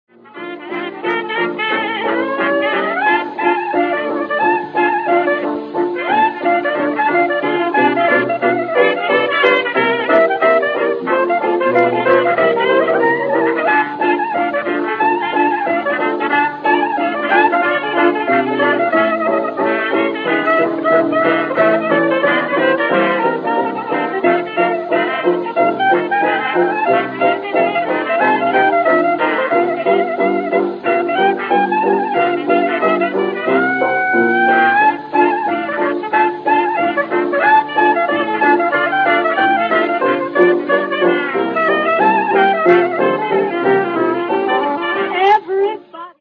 cornet
trombone
soprano sax
piano
vocal
banjo